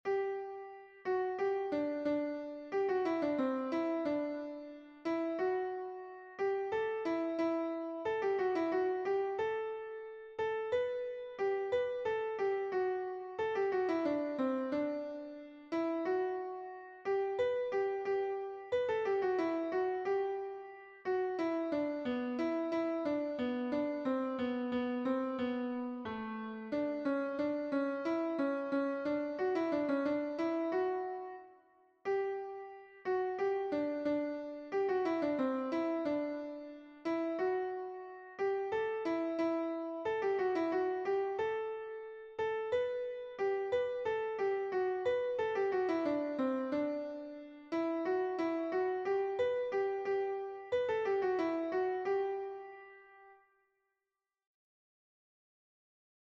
It's a sort of reflective, peaceful melody in G major, 4/4 time.